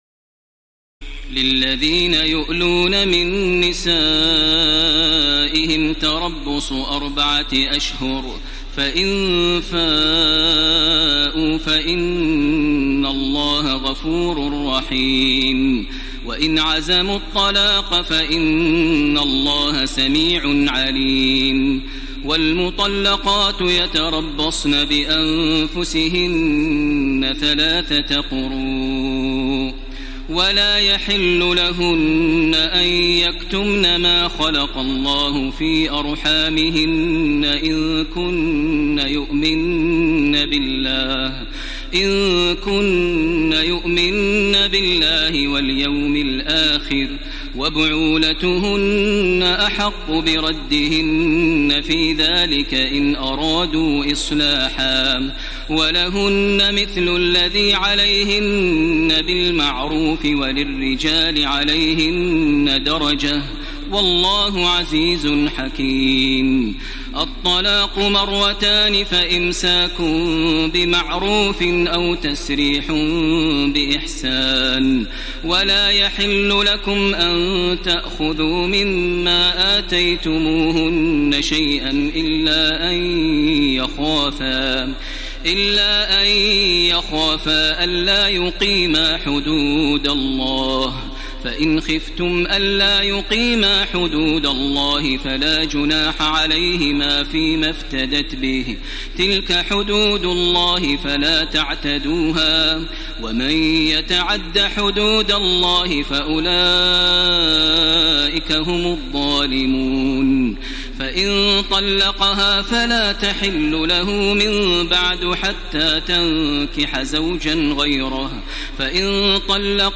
تراويح الليلة الثانية رمضان 1434هـ من سورة البقرة (226-271) Taraweeh 2 st night Ramadan 1434H from Surah Al-Baqara > تراويح الحرم المكي عام 1434 🕋 > التراويح - تلاوات الحرمين